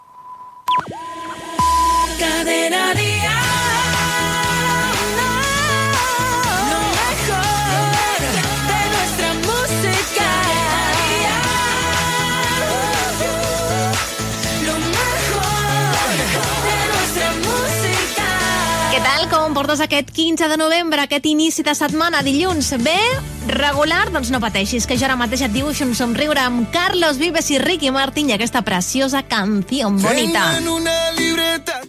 Indicatiu de l'emissora, data i tema musical.
Musical